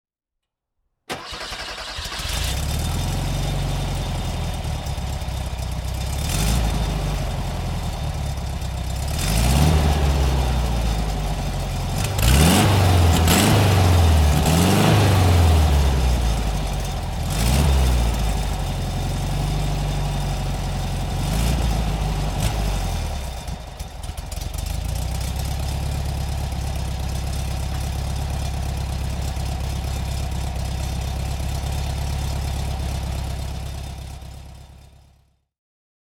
VW 1500 Automatic (1968) - Starten und Leerlauf